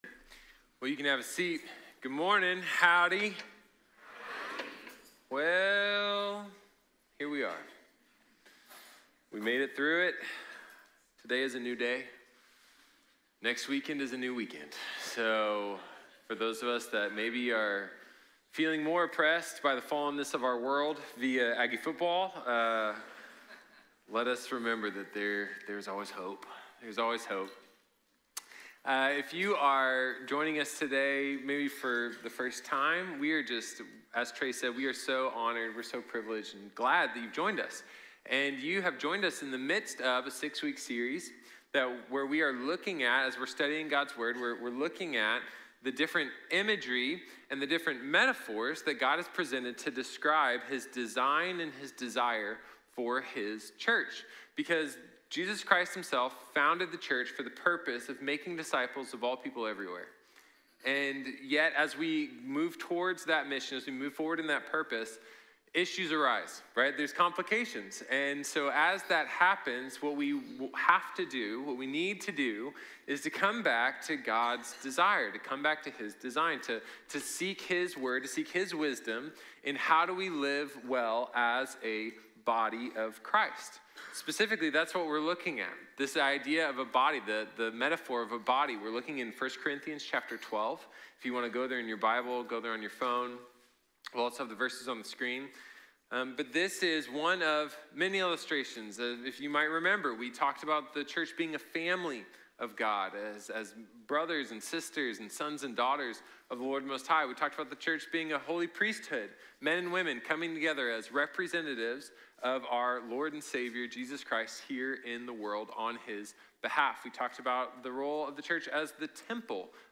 One Body | Sermon | Grace Bible Church